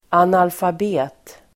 Ladda ner uttalet
analfabet.mp3